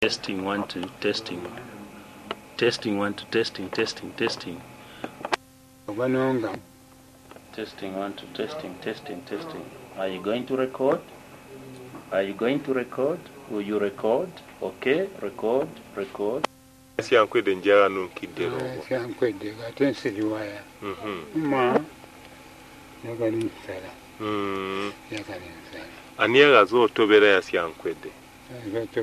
The oral interview covers an historical account of famine in Southern Part of Zambia between after 1920.